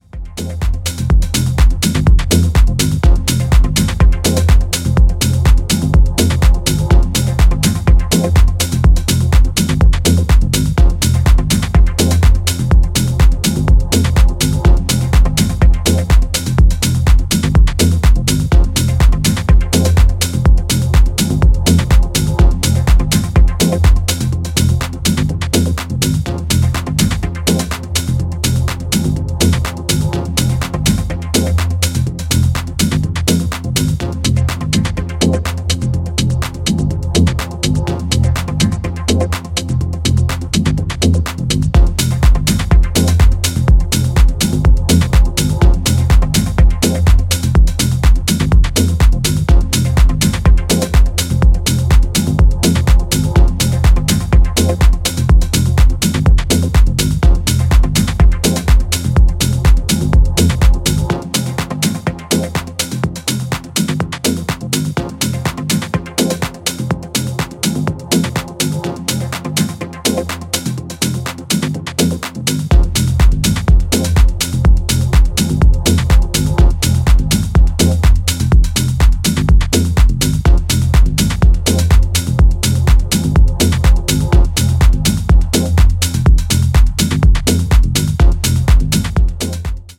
波打つベースラインのグルーヴ上で奥ゆかしいシンセレイヤーが展開される
全曲端正に作り込まれたミニマル・テック・ハウスに仕上がっています！